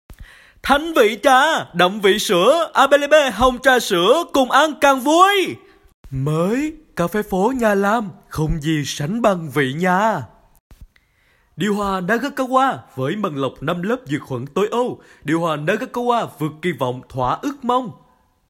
时尚广告-魅力女声